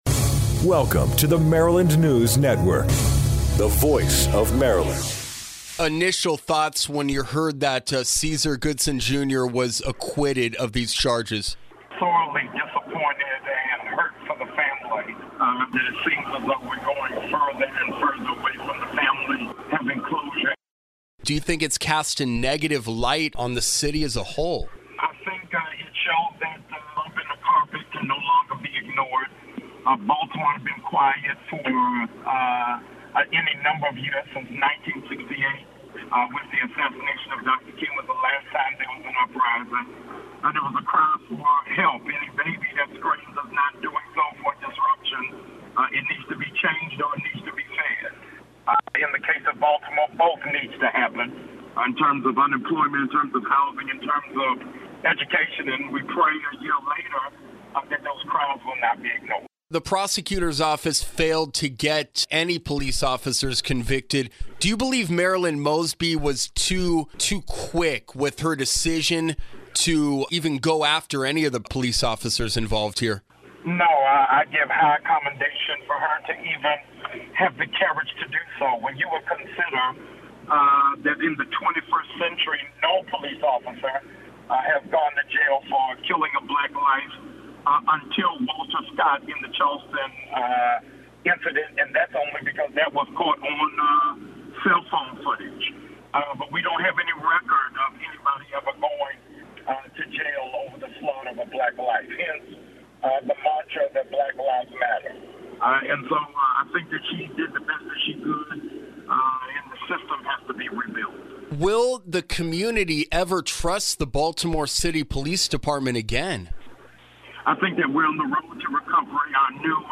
Immediately following the not guilty verdict against Baltimore Police Officer, Caesar Goodson Jr., The Maryland News Network talked to Dr. Jamal Bryant, Pastor of the Empowerment Temple AME Church in Baltimore. Bryants shares his views on State's Attorney, Marilyn Mosby, the future of the Baltimore, and getting justice for the Gray family.